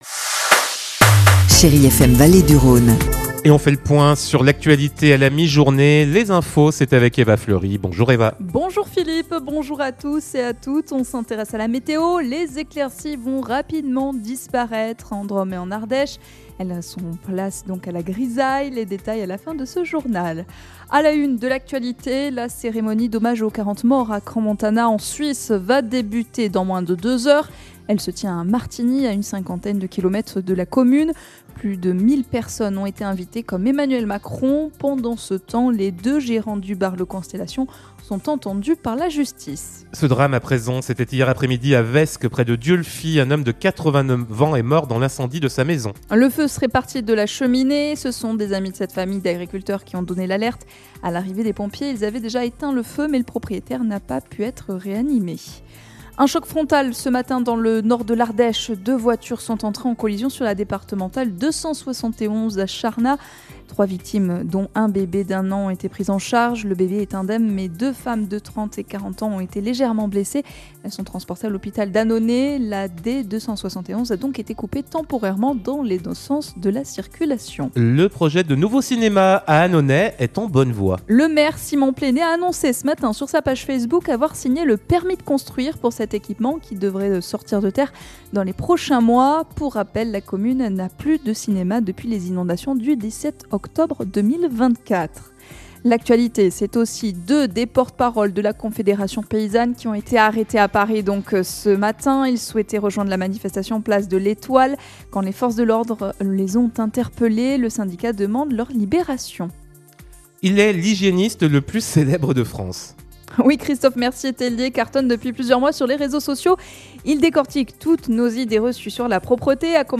Vendredi 9 janvier : Le journal de 12h